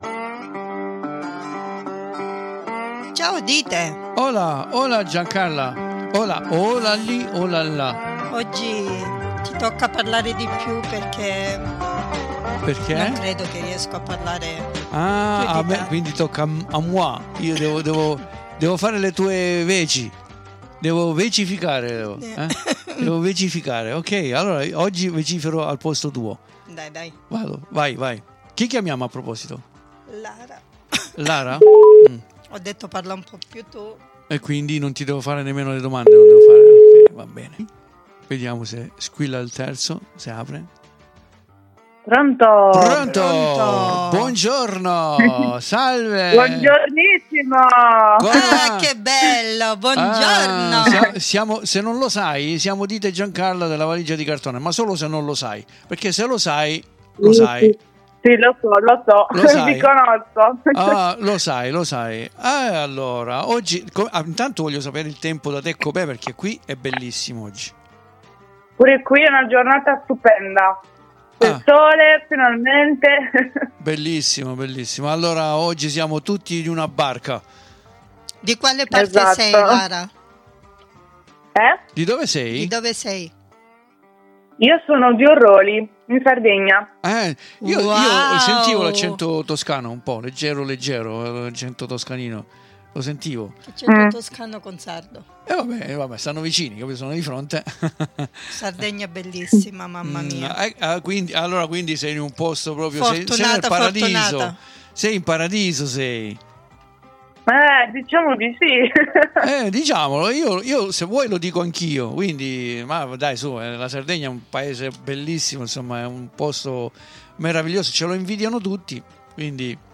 SIMPATICA E ALLEGRA SI INTRATTIENE CON NOI E CI PARLA DI LEI E DEI SUOI PROGETTI PASSATI DEI RECENTI E FUTURI!